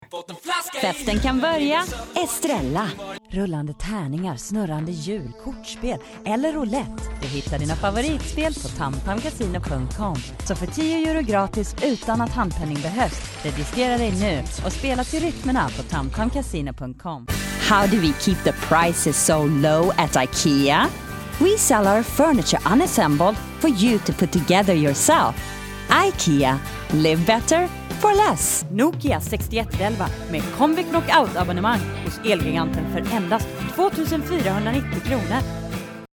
Swedish, North American, Positive, Warm, Smooth, Professional, Rich, Compassionate, Original, Worldly
Sprechprobe: Werbung (Muttersprache):